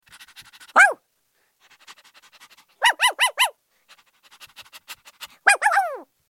دانلود آهنگ سگ 16 از افکت صوتی انسان و موجودات زنده
دانلود صدای سگ 16 از ساعد نیوز با لینک مستقیم و کیفیت بالا
جلوه های صوتی